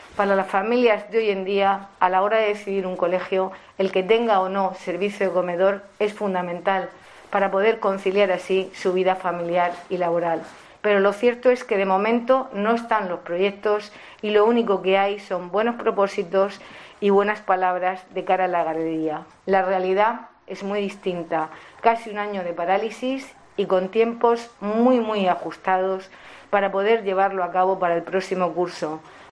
Belén López, Concejal del PP en el Ayuntamiento de Murcia